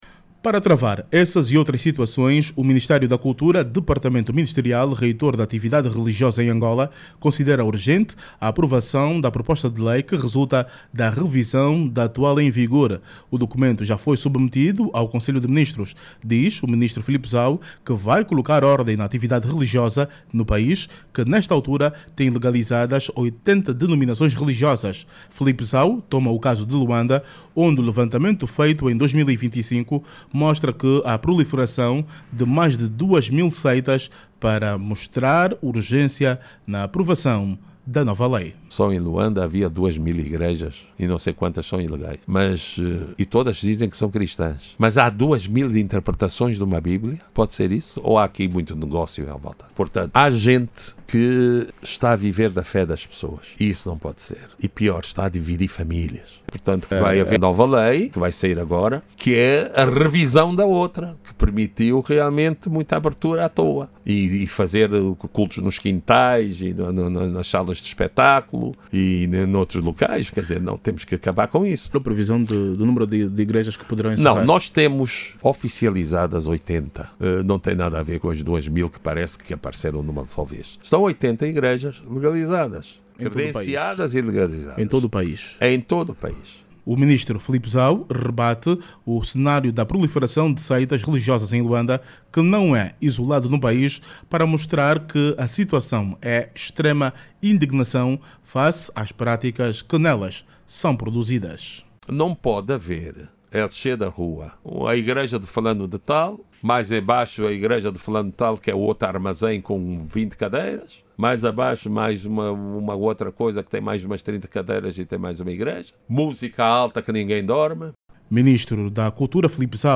Saiba mais dados no áudio abaixo com o repórter